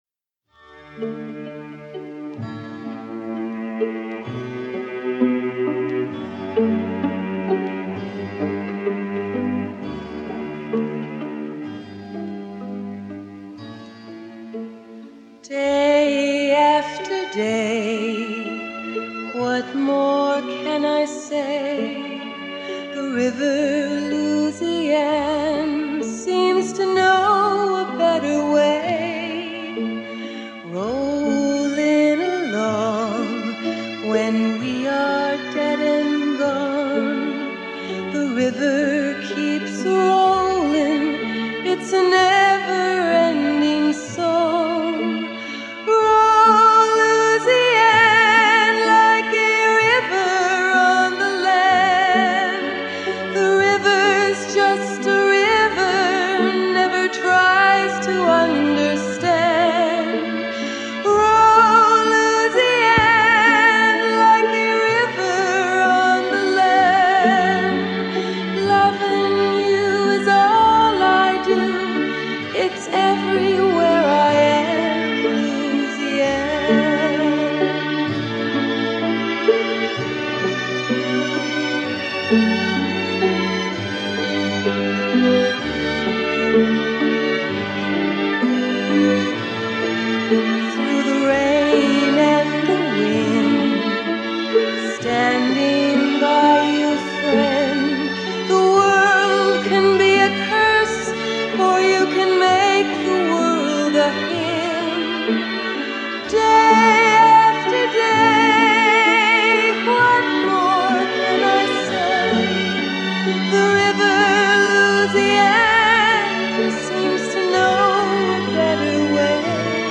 clean and pure.